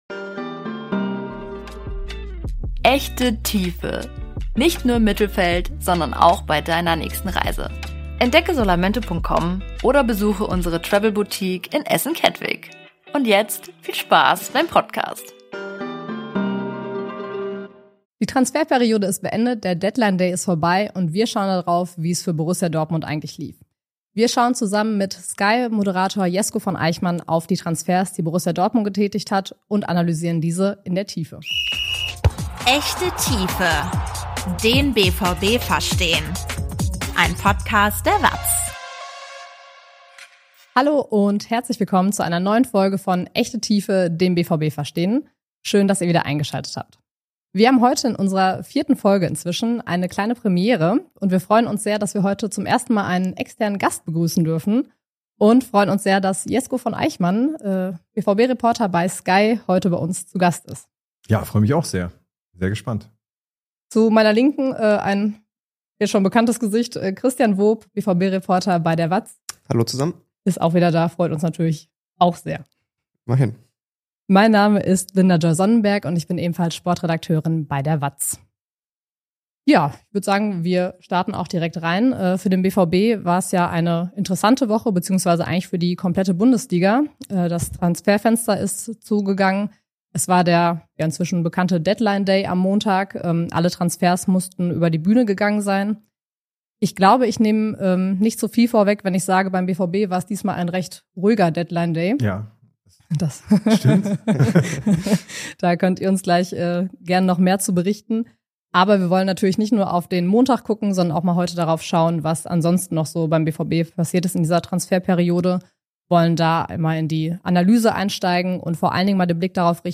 BVB-Talk
BVB-Reporter diskutieren ein Thema in der Tiefe